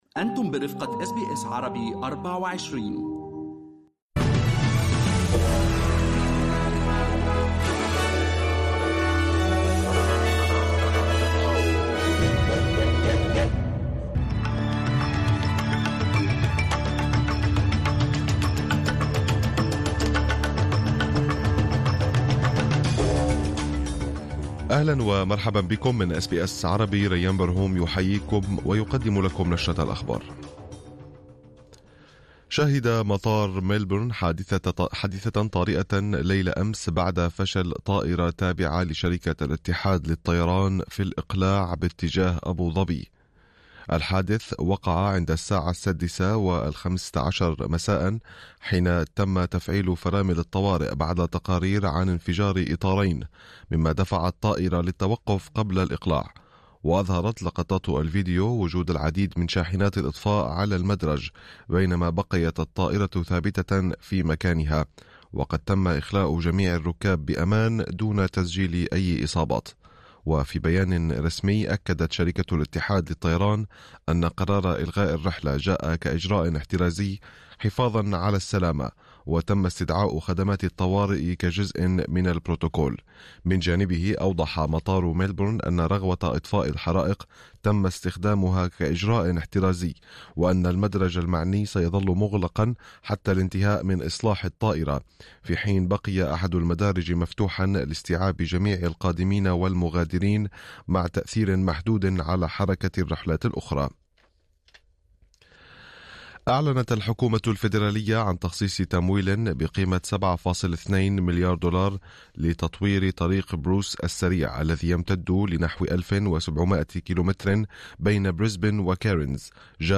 نشرة أخبار الظهيرة 06/01/2025